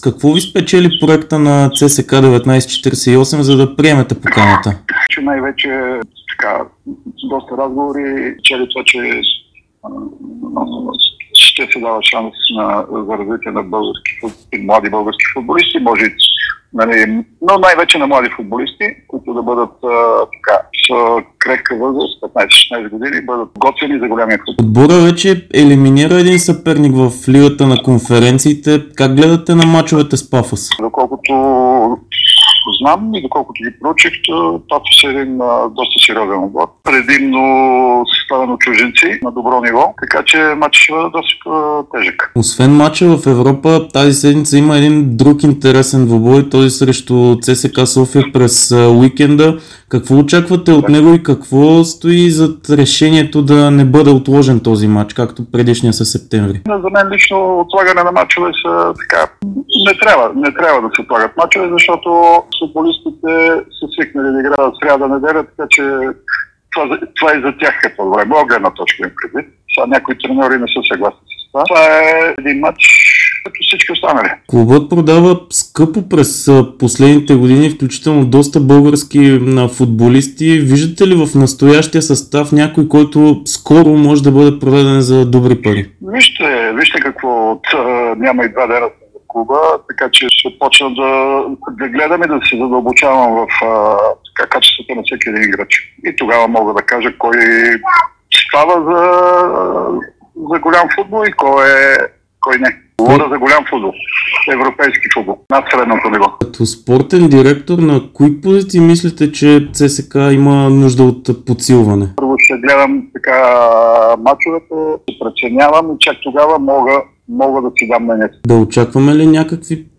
Емил Костадинов даде първото си интервю като спортен директор на ЦСКА 1948 пред dsport и Дарик радио. Той говори за проекта на клуба, предстоящите мачове с Пафос и ЦСКА София, трансферната политика, първите си впечатления и целите пред „червените“.